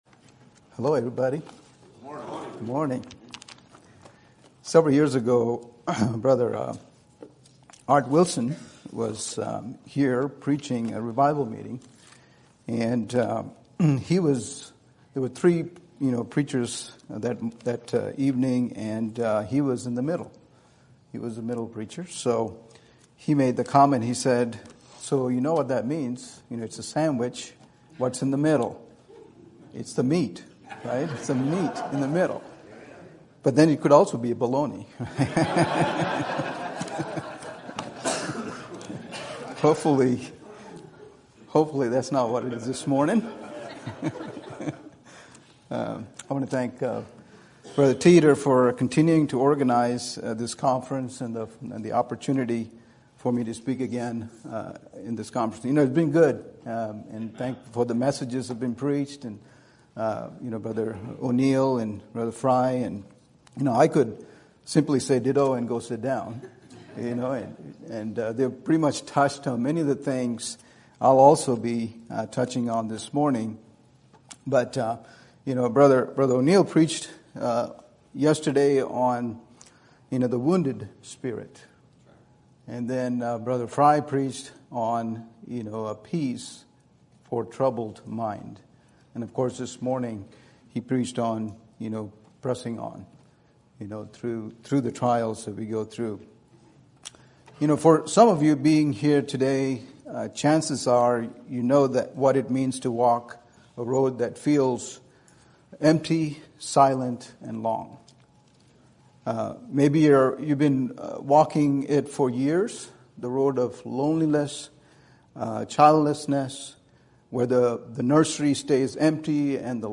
Sermon Topic: Lonely Road Conference Sermon Type: Special Sermon Audio: Sermon download: Download (15.01 MB) Sermon Tags: Job Help Lonely Yet